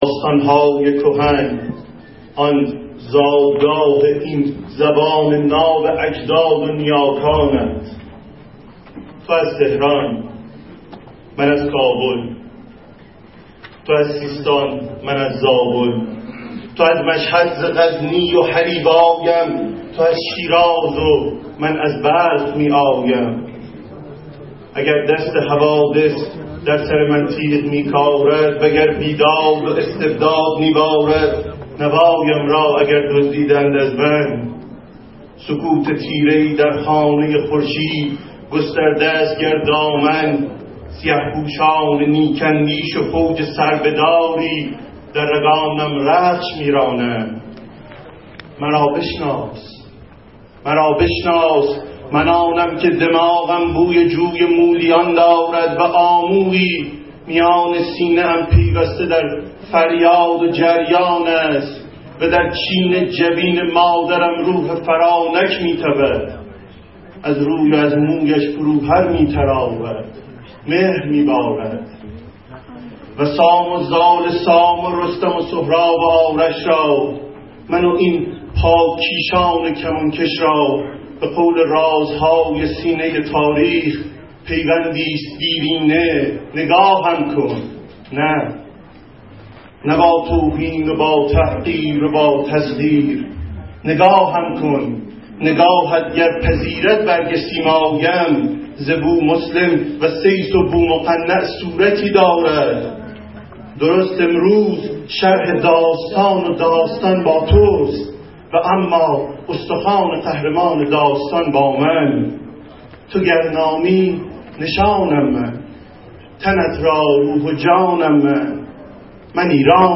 سخنرانی
در سالن فردوسی خانه اندیشمندان علوم انسانی